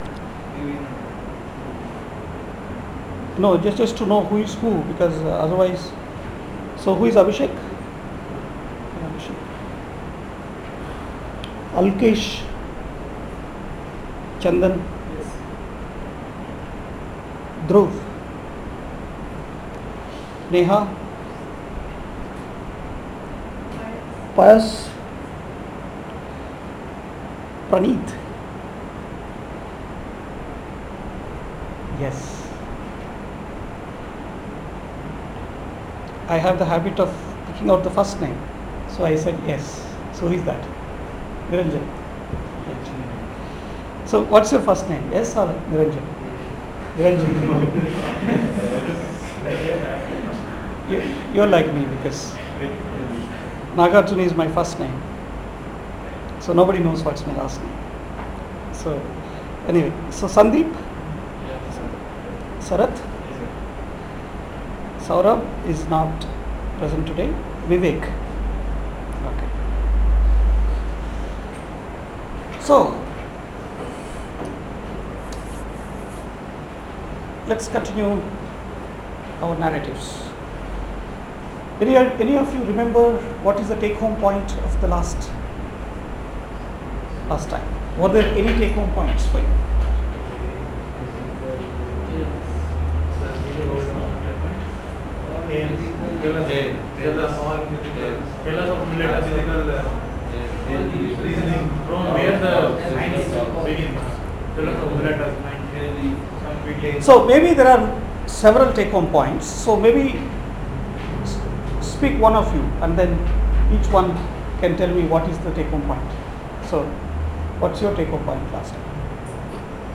lecture 2